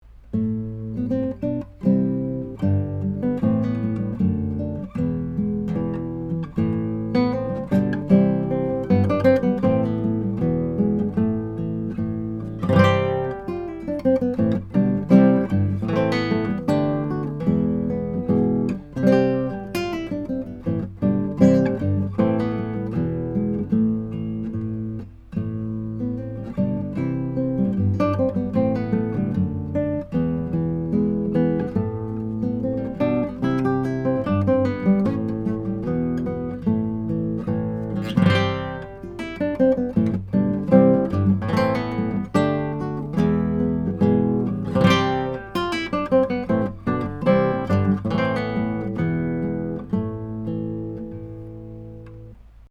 Archtop
This is a fantastic hand-made archtop guitar with beautiful full-range tone, excellent volume and projectipon, along with nice, low action. 1930s Gibsons, even the low-end models like the L50 currently sell for $1,500.
Recorded with a couple of prototype TAB Funkenwerk / German Masterworks CG•OA-1 condenser mics into a Trident 88 recording console using Metric Halo ULN8 converters.